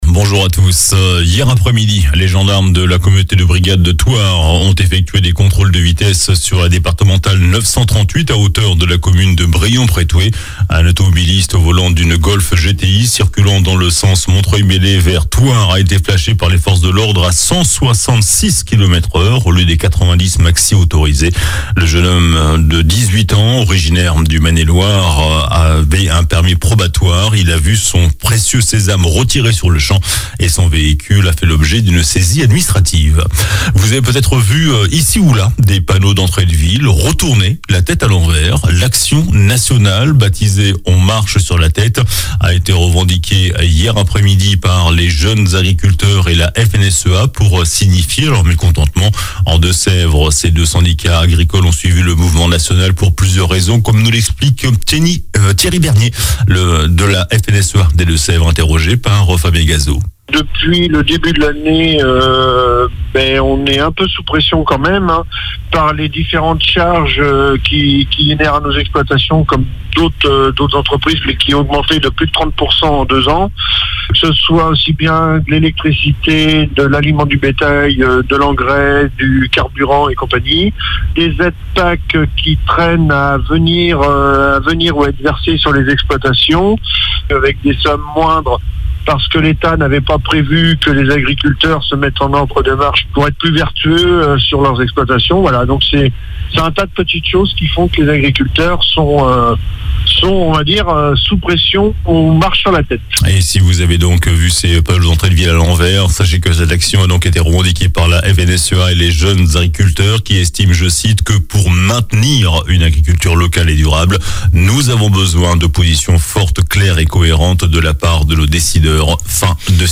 Journal du samedi 18 novembre